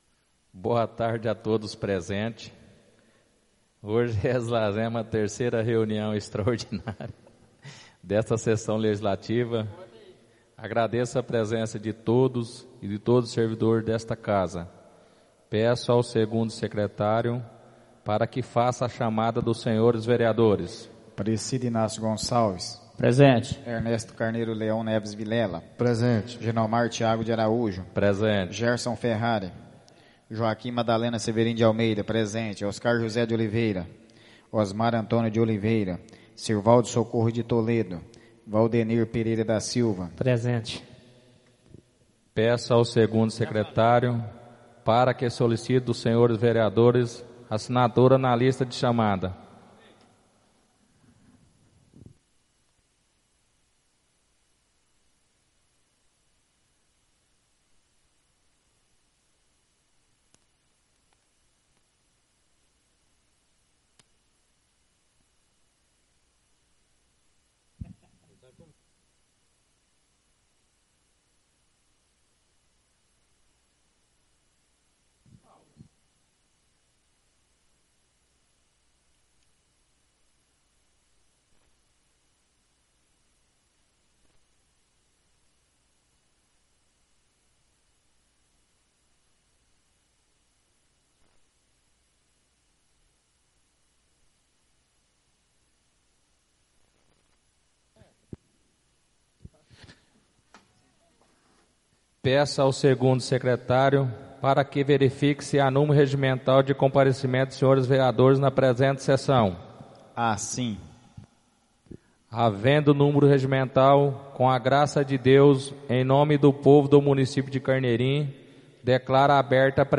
Áudio da 3ª (terceira) sessão extraordinária de 2016, realizada no dia 12 de Maio de 2016, na sala de sessões da Câmara Municipal de Carneirinho, Estado de Minas Gerais.